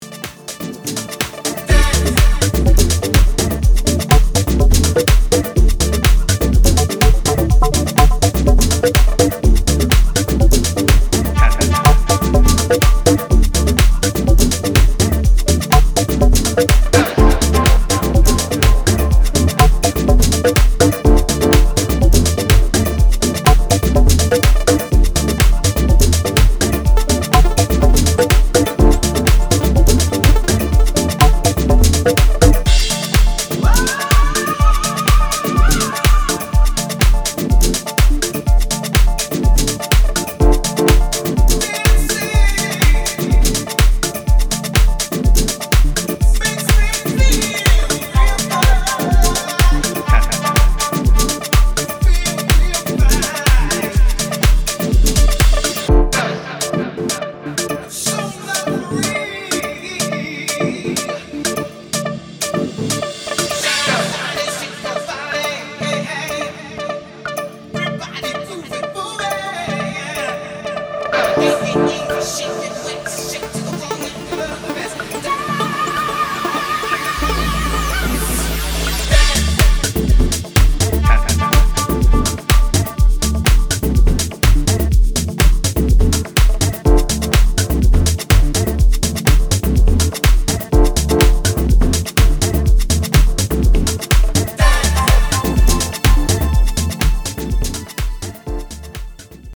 ディープハウス/ソウルをグルーヴィーに融合させる彼の持ち味が凝縮されています。